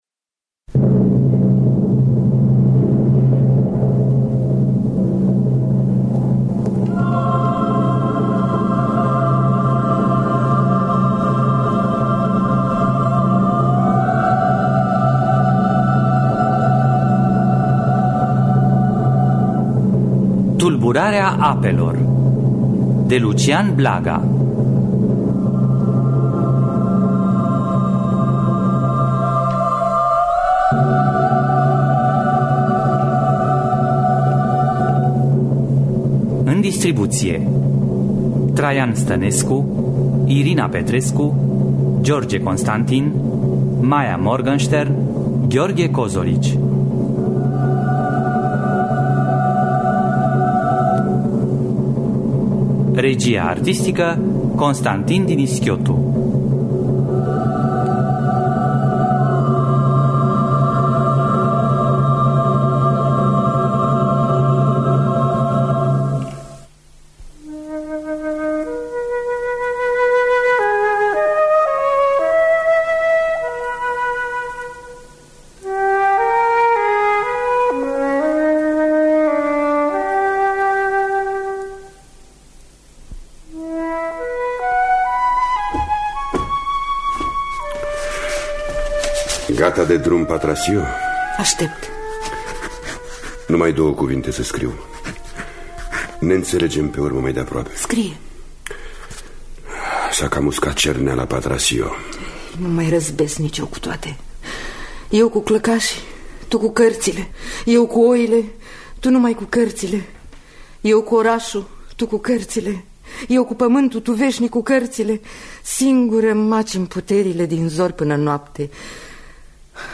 “Tulburarea apelor” de Lucian Blaga – Teatru Radiofonic Online
Adaptarea radiofonică